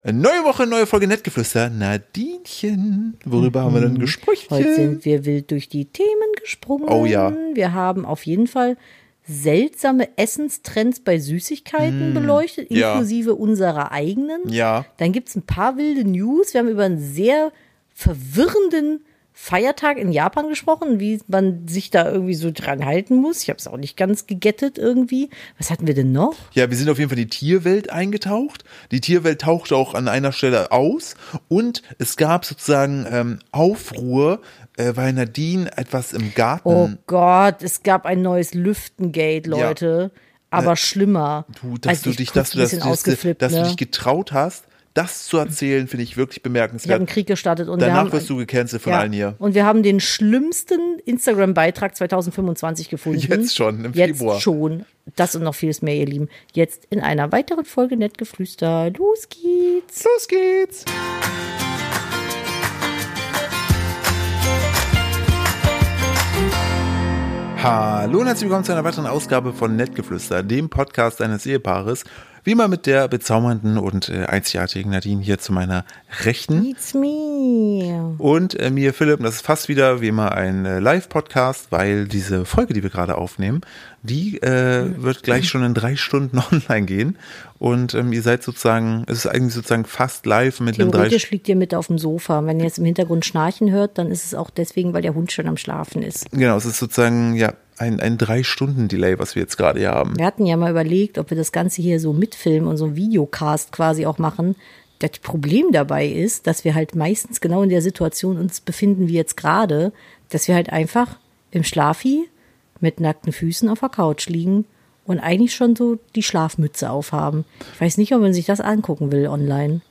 Wir sind ein Familien- und Ehepaar Podcast und nutzen die Zeit, um alltägliche Dinge zu besprechen, für die uns oft einfach die Zeit fehlt.